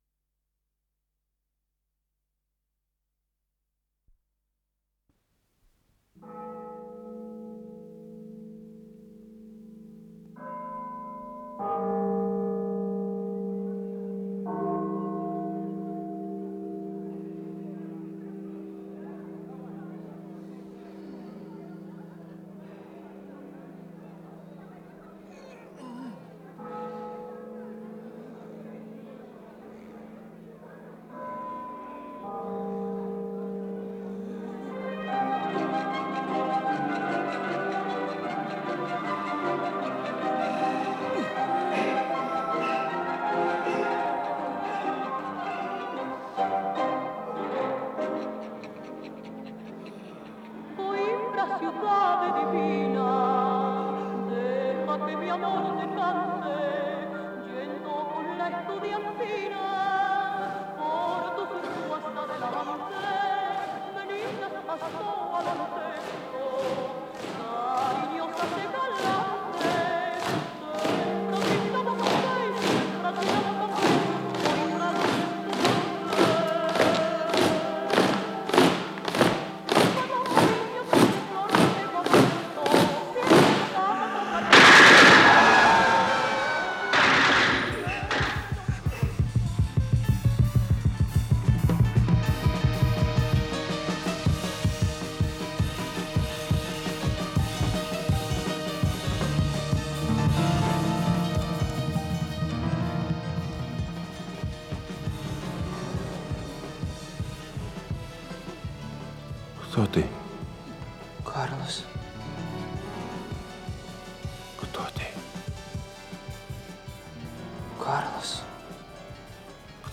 Исполнитель: Артисты Московских театров Студенты Государствнного педагогического института иностранных языков им. М. Тореза
Радиоспектакль в 2-х частях, часть 1-я